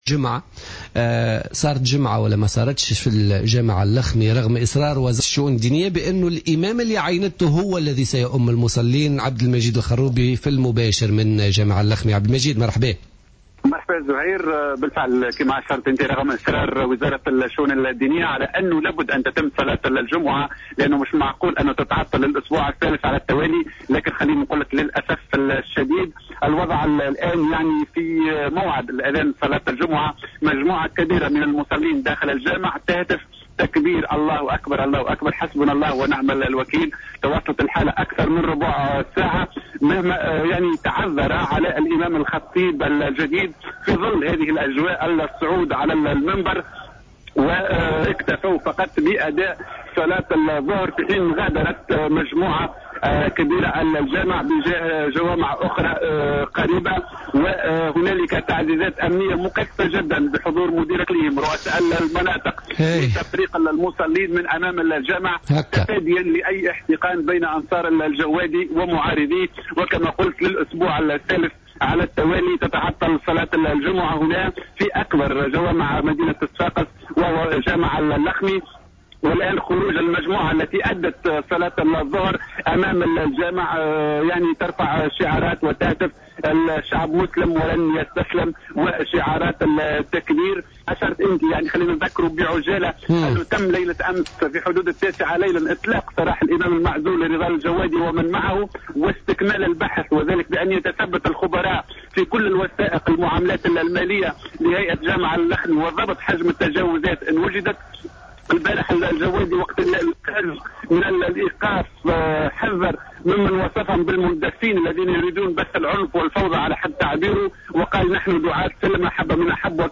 أكثر تفاصيل مع مراسلنا